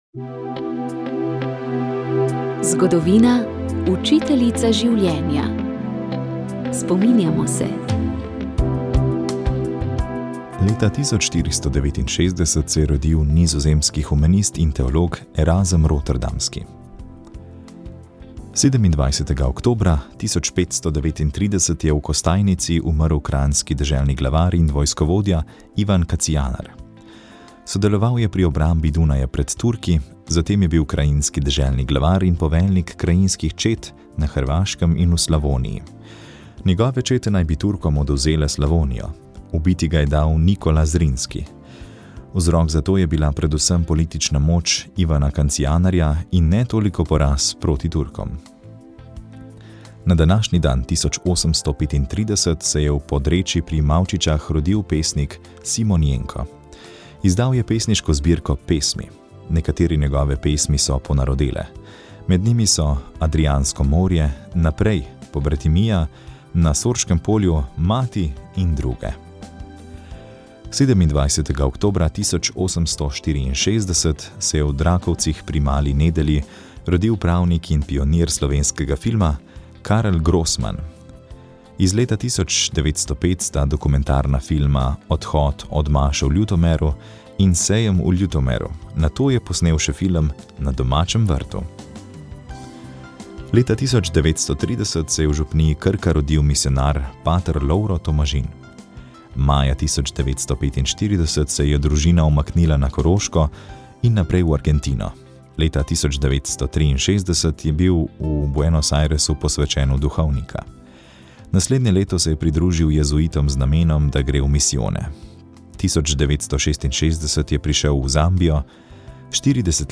Sv. maša iz stolne cerkve sv. Janeza Krstnika v Mariboru dne 22. 11.
Sveto mašo je daroval mariborski nadškof Alojzij Cvikl.